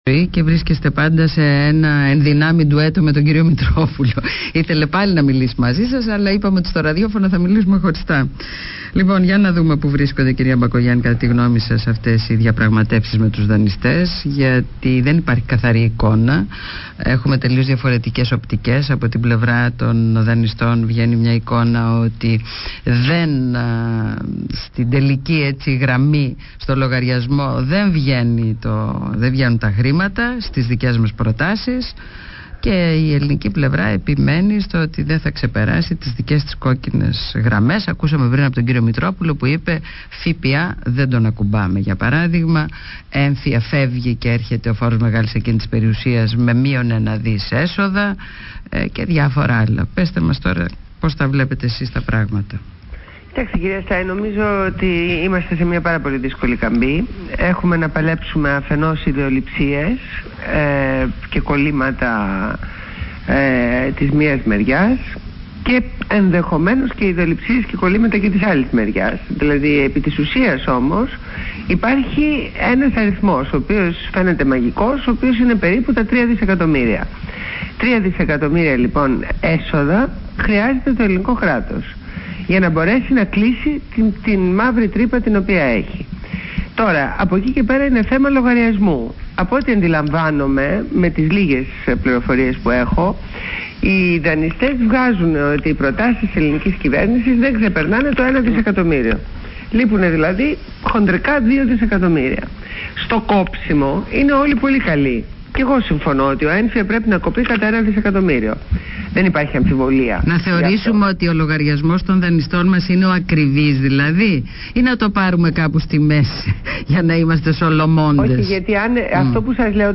Συνέντευξη στο ραδιόφωνο Παραπολιτικά 90,1fm με την Ε. Στάη